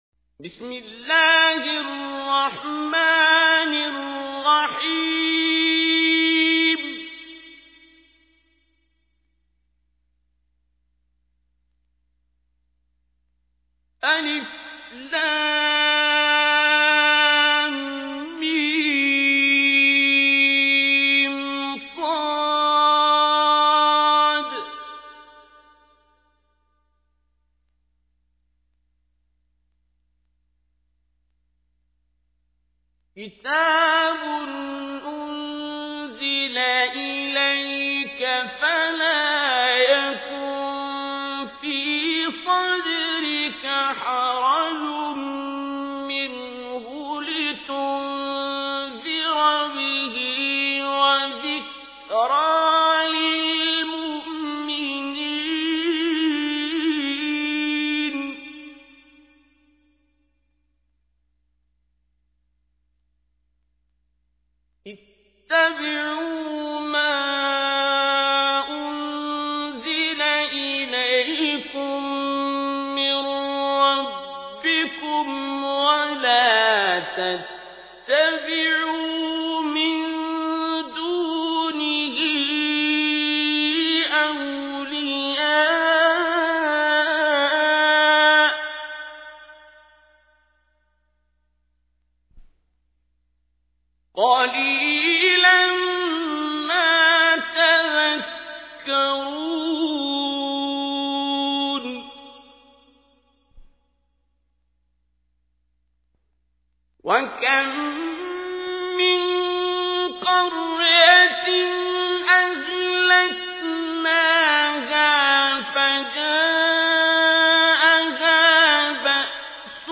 تحميل : 7. سورة الأعراف / القارئ عبد الباسط عبد الصمد / القرآن الكريم / موقع يا حسين